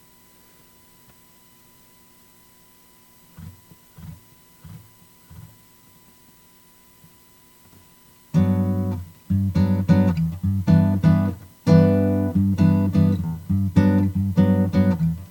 these are MP3s of silence then a short guitar track…I dont know how to send you a picture of the wave form...if you could explain that to me it would be helpful..basically there is hum on my recordings that Im almost positive are internal…I turn off everything in the room when recording including the cooling pad under the computer
the 1st example is with no noise reduction